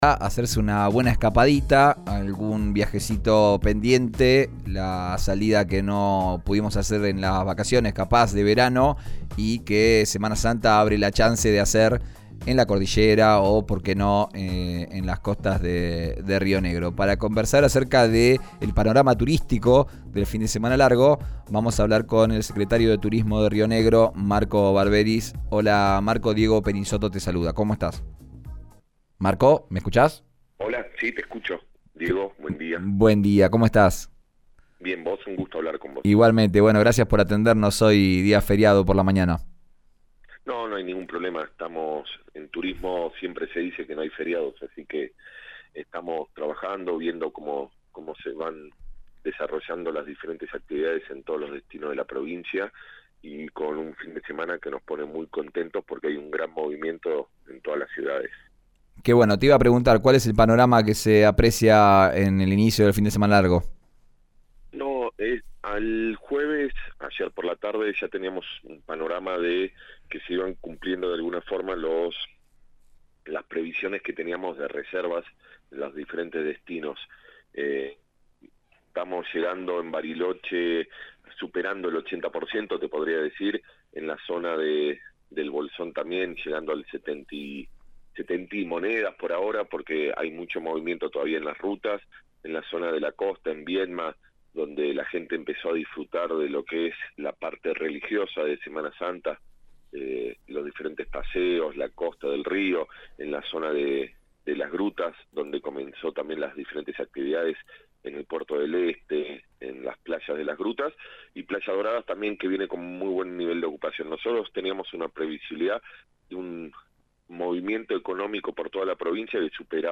Escuchá al secretario de Turismo de Río Negro, Marcos Barberis, en RÍO NEGRO RADIO: